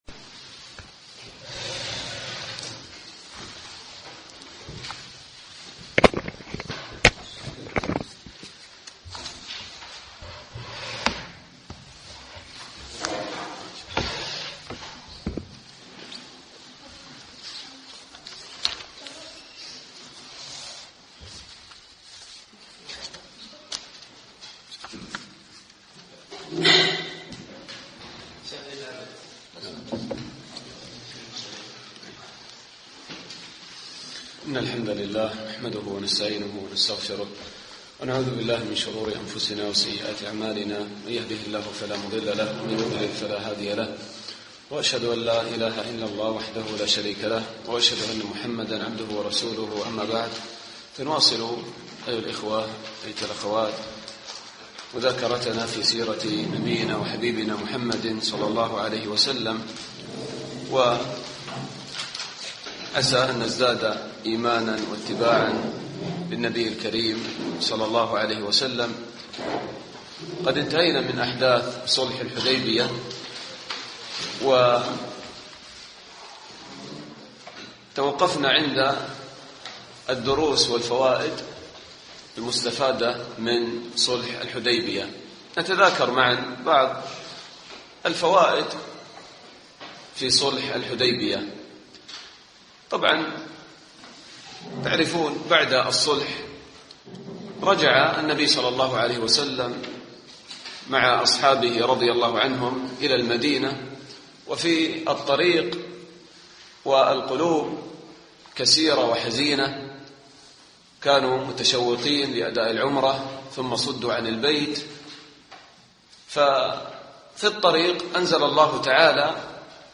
الدرس السادس عشر